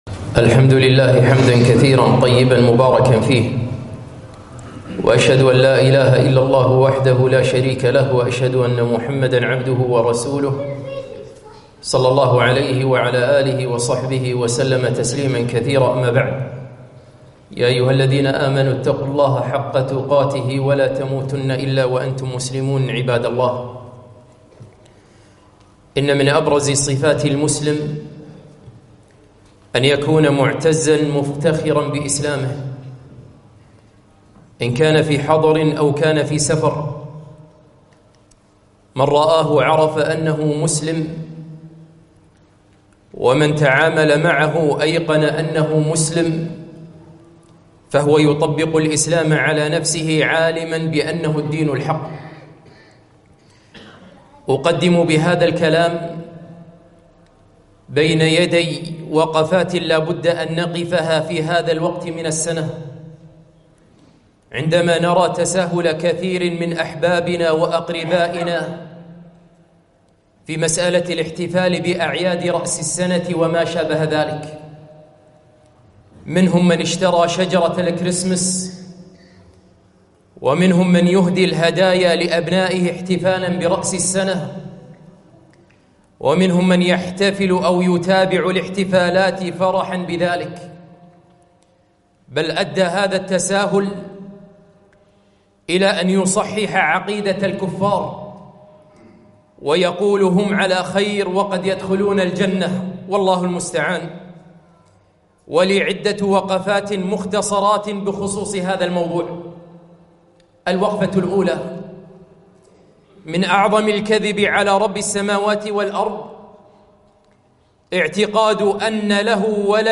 خطبة - موقف المسلم من رأس السنة